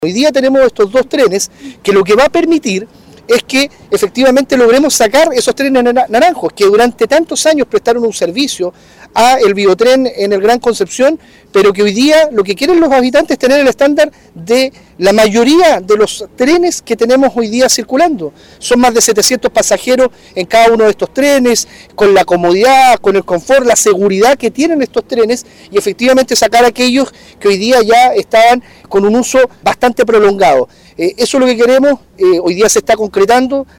En tanto, el delegado presidencial regional, Eduardo Pacheco, destacó que los plazos y los compromisos se han ido cumpliendo de acuerdo con lo esperado, además de la modernización que implica la entrada en operación de ambas máquinas ferroviarias.